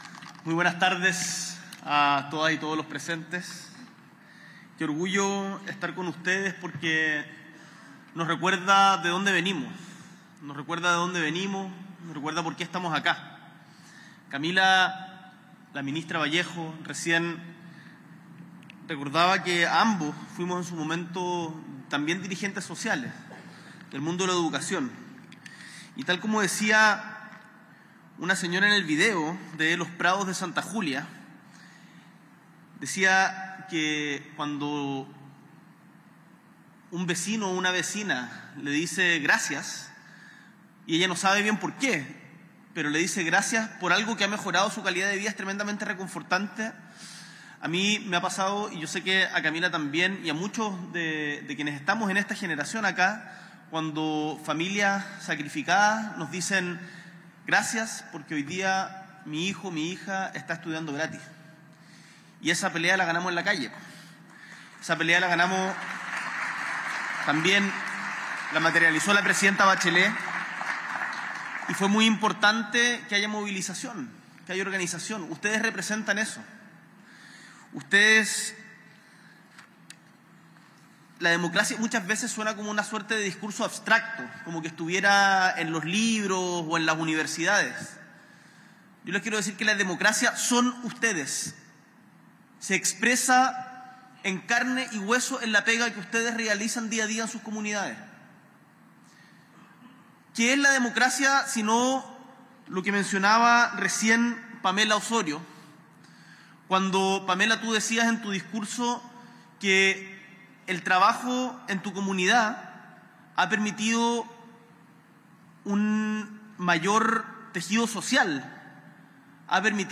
S.E. el Presidente de la República, Gabriel Boric Font, encabeza la primera ceremonia de entrega del Premio Anual Dirigencia Social y Comunitaria
Discurso